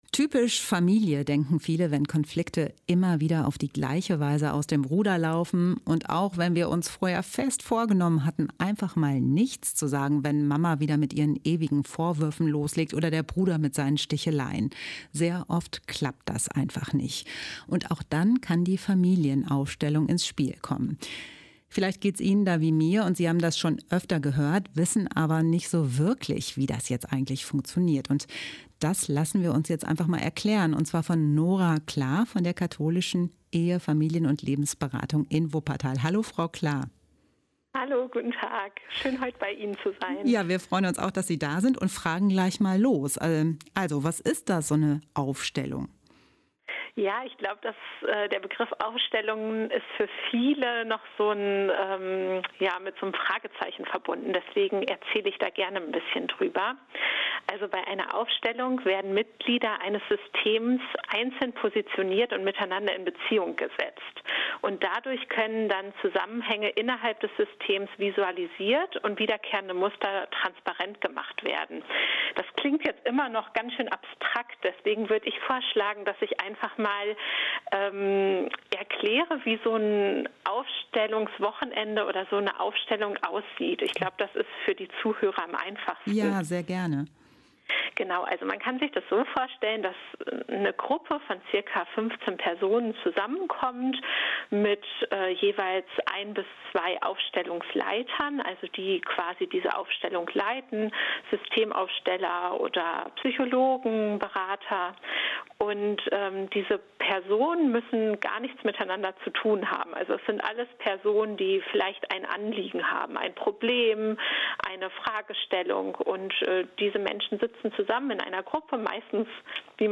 Ein Interview